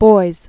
(boiz, bois), Joseph 1921-1986.